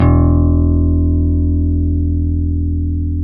Index of /90_sSampleCDs/Roland LCDP02 Guitar and Bass/BS _E.Bass 5/BS _Dark Basses